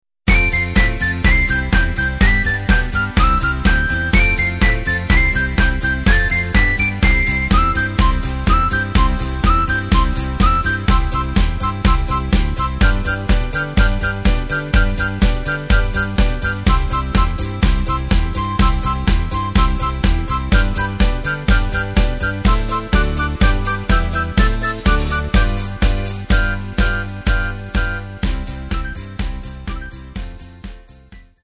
Takt:          2/4
Tempo:         124.50
Tonart:            C
Gaudi-Song aus dem Jahr 1972!
Playback mp3 Demo